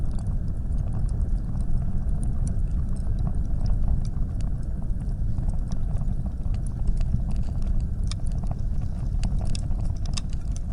torch.ogg